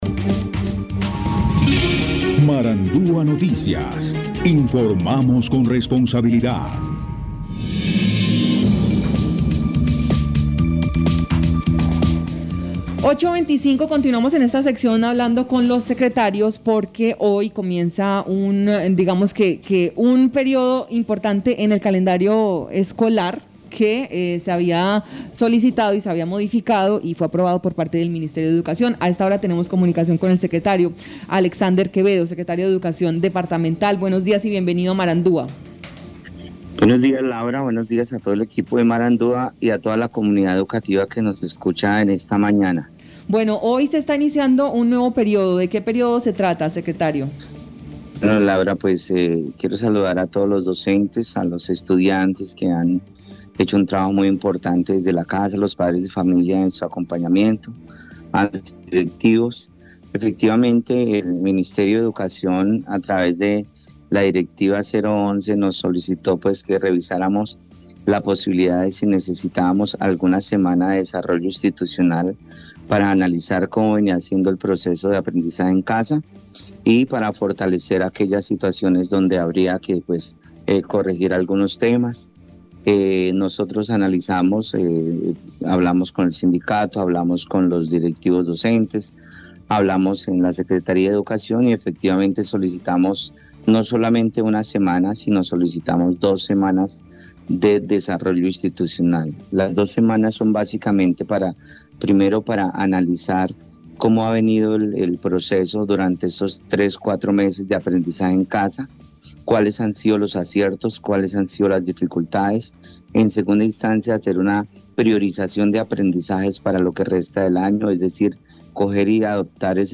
Escuche a Alexander Quevedo, secretario de Educación del Guaviare.